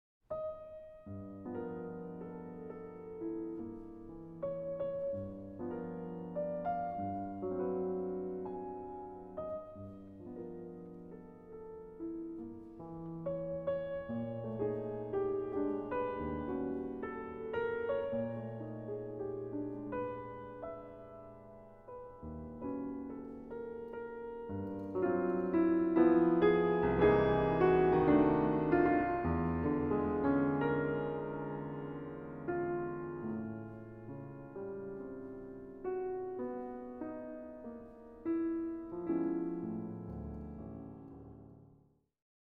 Sonata for Piano